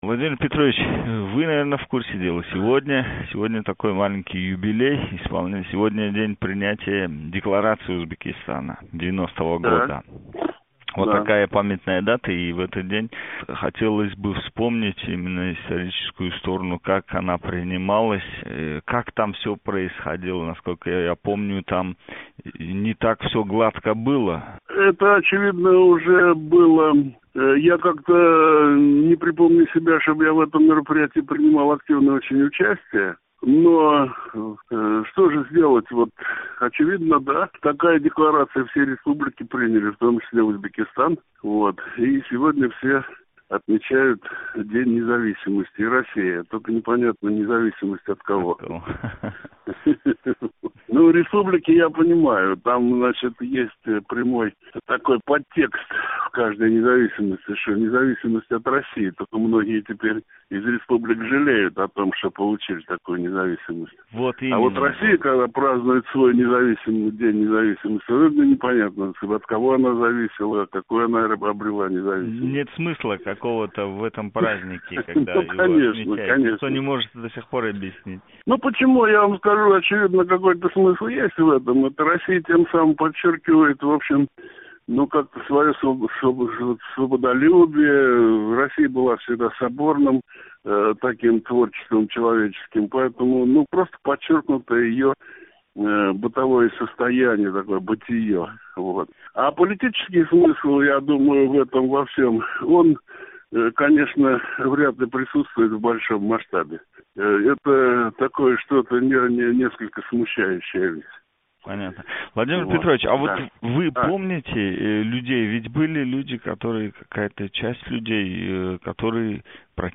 Владимир Анишчев билан суҳбат